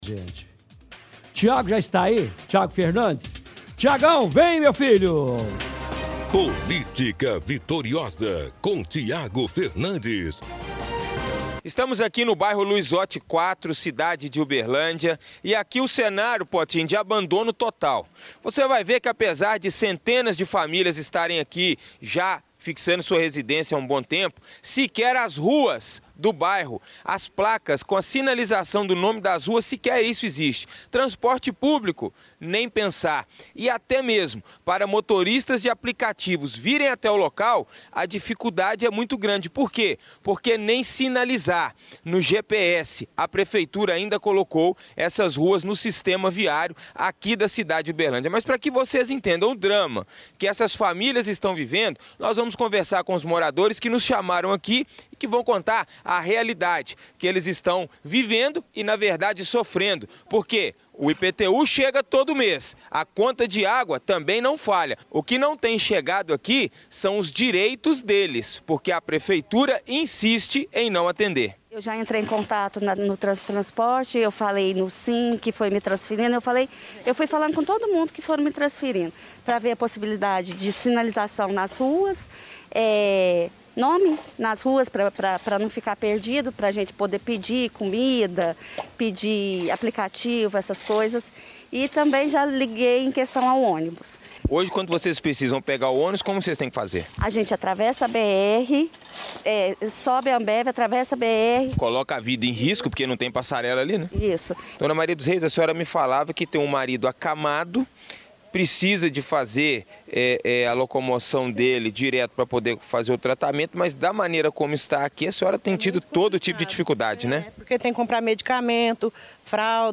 – Transmissão de áudio da reportagem de hoje do Chumbo Grosso.